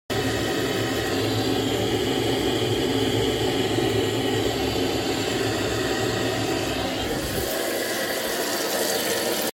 Fort bruit dans mes canalisations
En effet depuis l'achat et la rénovation de ce dernier je suis surpris du fort bruit lors du tirage de l'eau.
Voici un enregistrement avec le bruit dans la cuisine :
bruit-des-canalisations.mp3